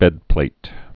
(bĕdplāt)